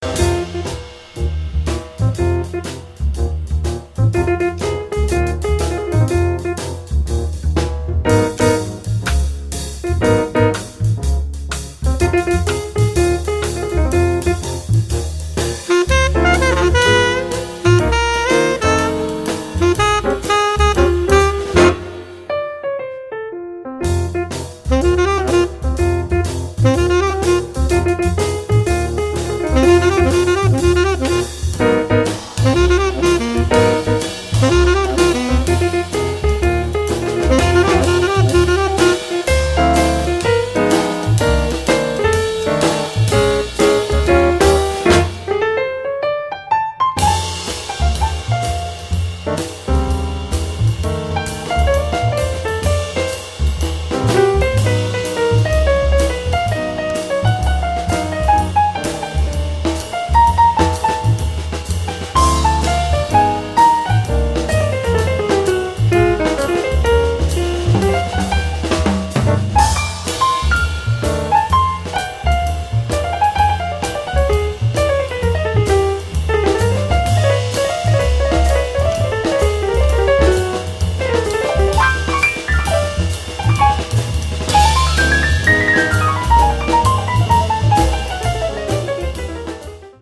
sax soprano e tenore
pianoforte
contrabbasso
batteria